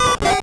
Cri de Coxyclaque dans Pokémon Or et Argent.